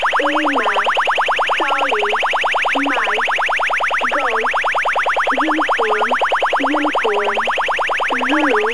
Bubbler глушит станцию E10